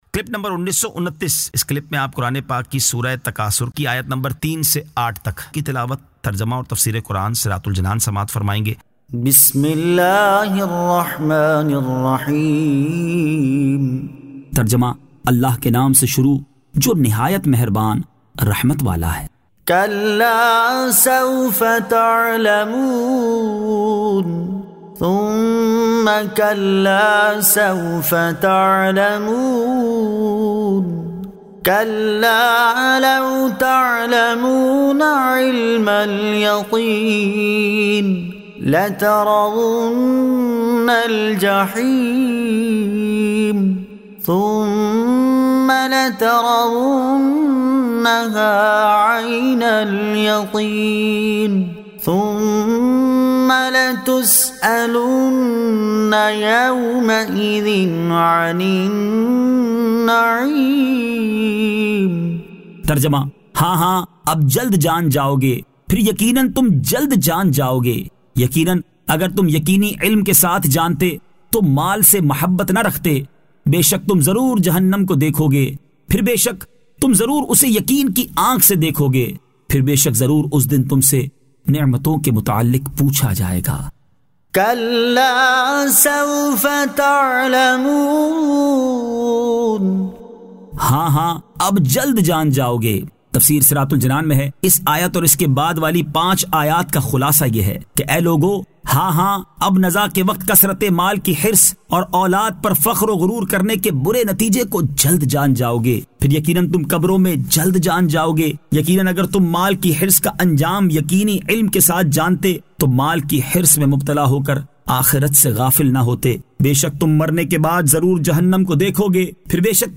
Surah At-Takathur 03 To 08 Tilawat , Tarjama , Tafseer